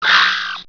harpy
pain1.wav